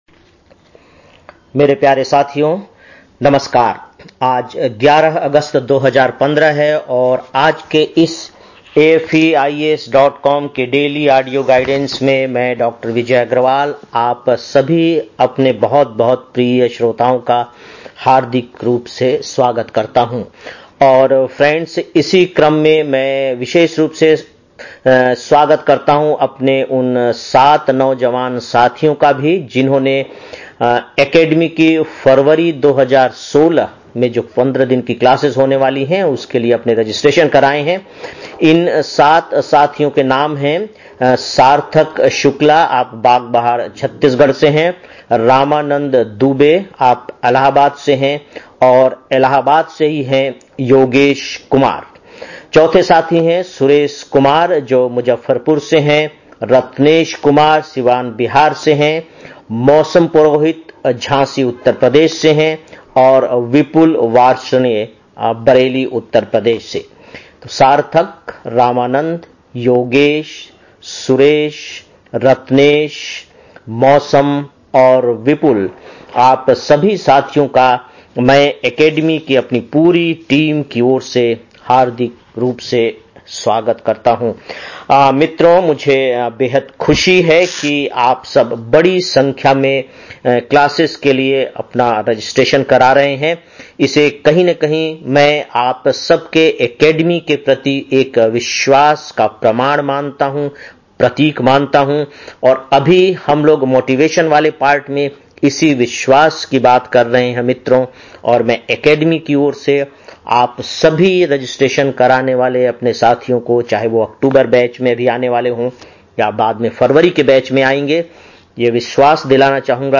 11-08-15 (Daily Audio Lecture) - AFEIAS